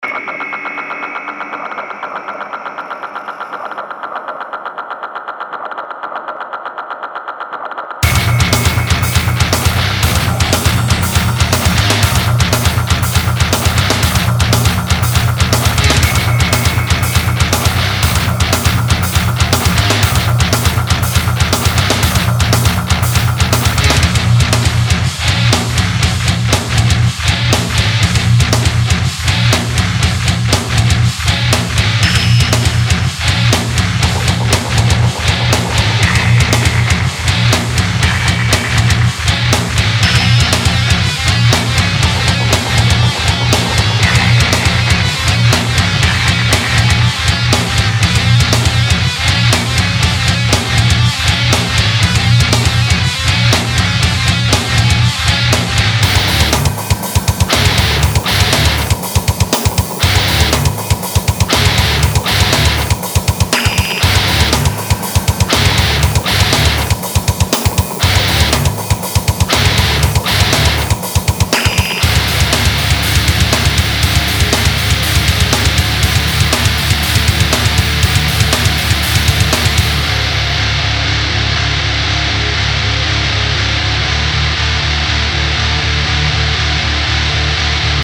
pod x3.mf.scabre pre bass , ������� �������� ����!(industrial metal)